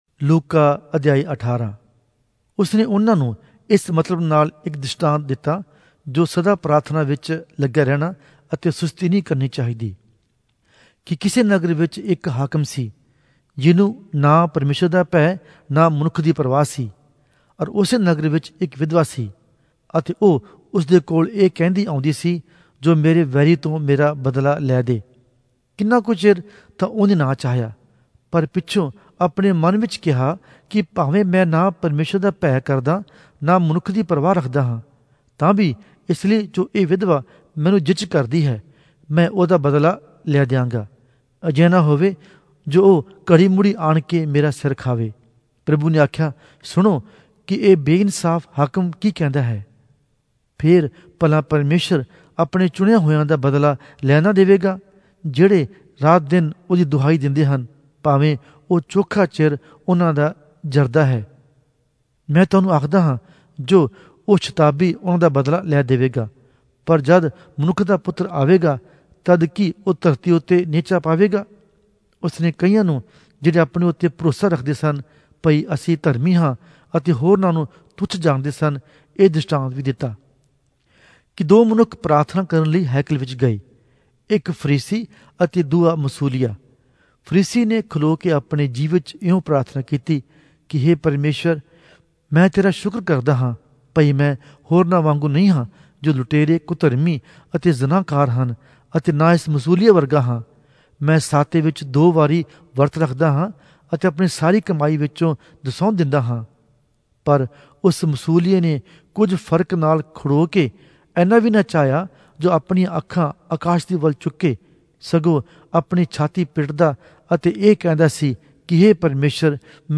Punjabi Audio Bible - Luke 15 in Gntwhrp bible version